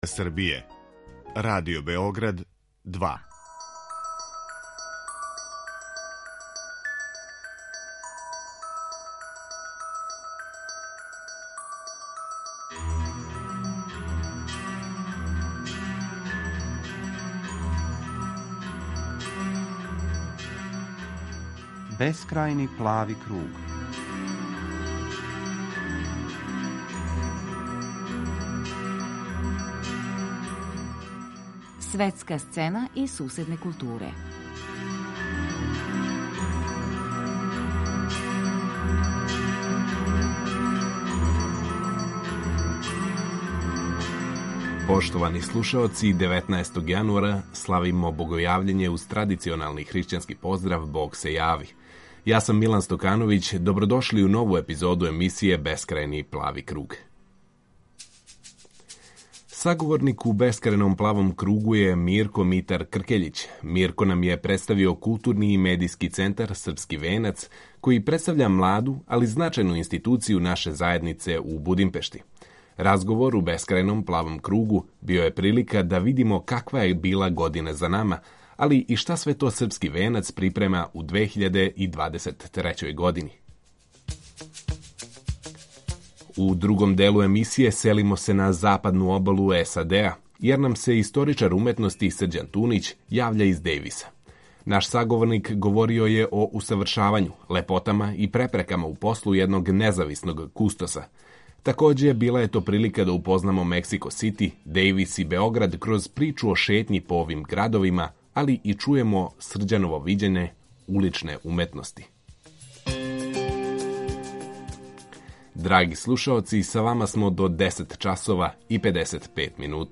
Разговор у Бескрајном плавом кругу био је прилика да видимо каква је била година за нама, као и шта све то „Српски венац" припрема у 2023. години.